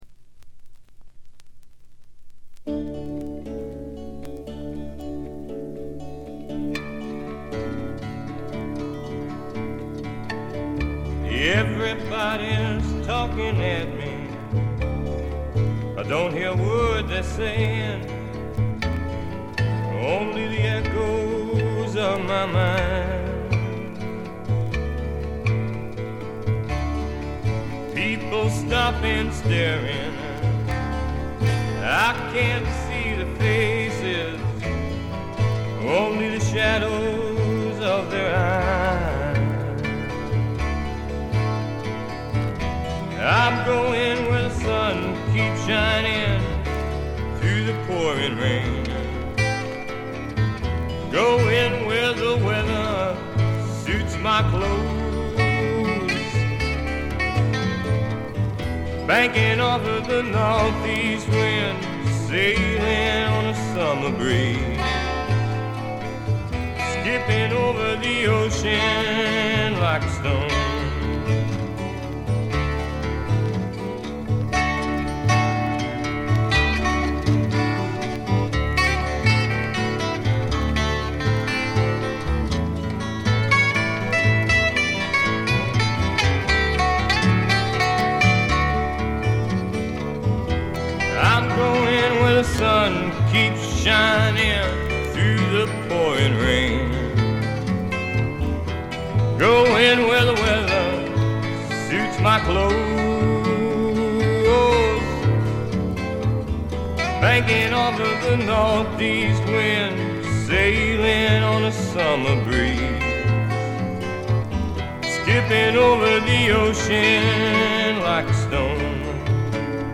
ごくわずかなノイズ感のみ。
言わずとしれた60年代を代表するフォーク／アシッド・フォークの大名作ですね。
地を這うように流れ出すヴォーカルには底なし沼の深淵に引きずり込まれるような恐怖とぞくぞくする快感を覚えます。
モノラル盤。
試聴曲は現品からの取り込み音源です。